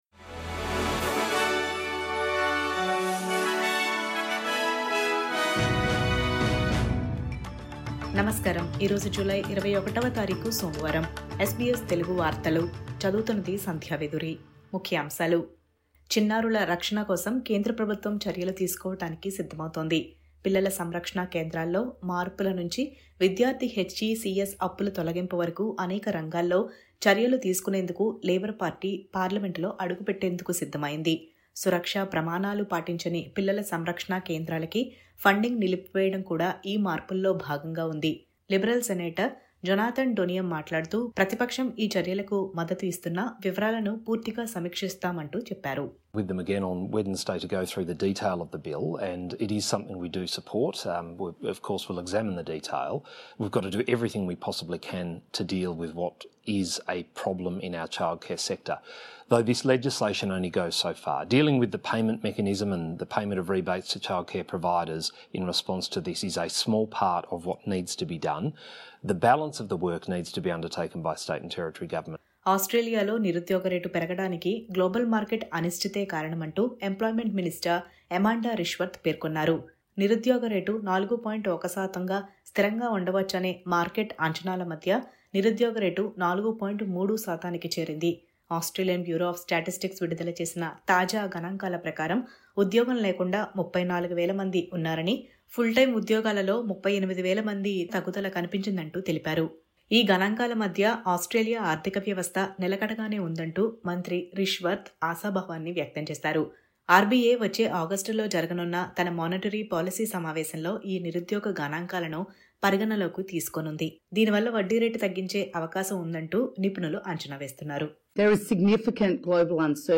News Update: సురక్ష ప్రమాణాలు పాటించని చైల్డ్‌కేర్ సెంటర్ల నిధులపై వేటు వేయనున్న కేంద్రం..